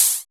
HH HH 89.wav